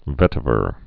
(vĕtə-vər)